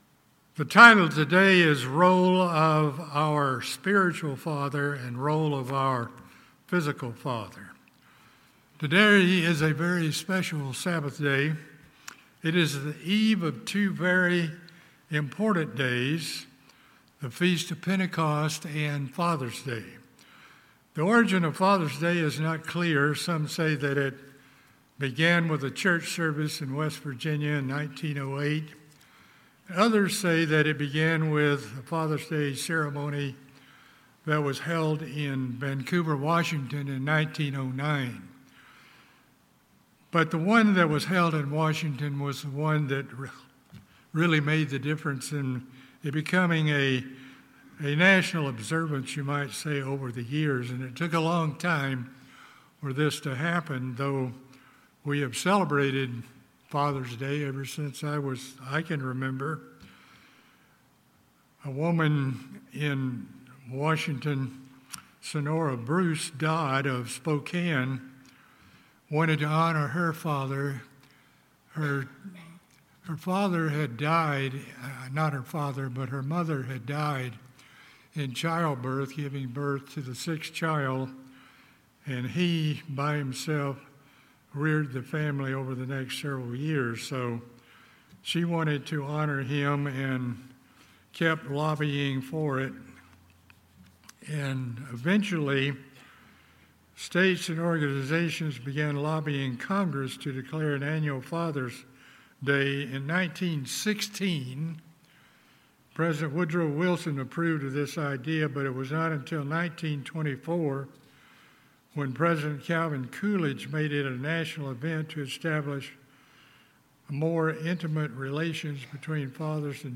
Today is a special Sabbath, it is the eve of Pentecost and Father's Day.